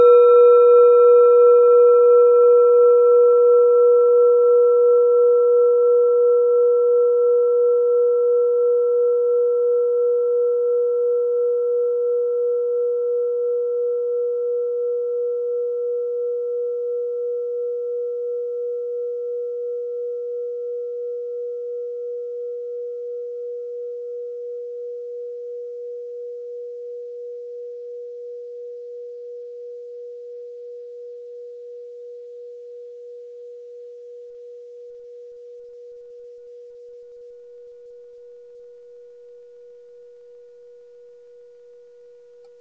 Sie ist neu und wurde gezielt nach altem 7-Metalle-Rezept in Handarbeit gezogen und gehämmert.
Hörprobe der Klangschale
(Ermittelt mit dem Minifilzklöppel)
Klangschalen-Gewicht: 290g
kleine-klangschale-19.wav